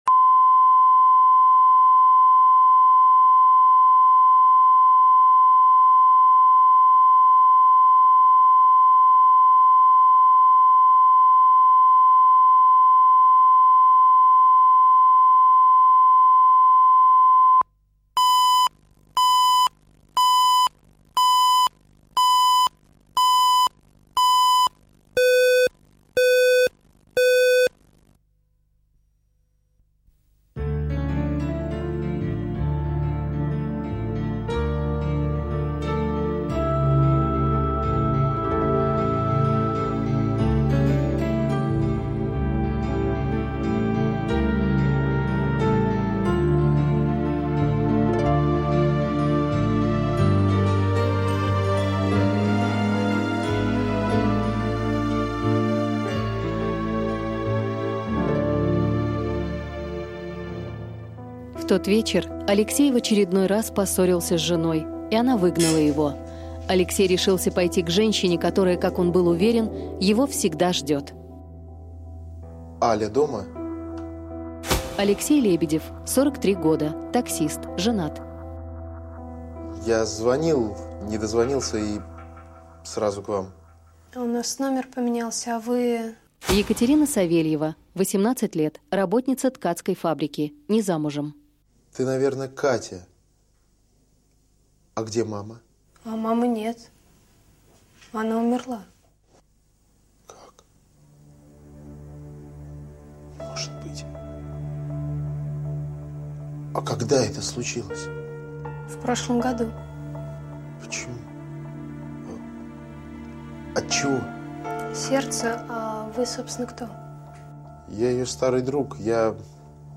Аудиокнига Командировка | Библиотека аудиокниг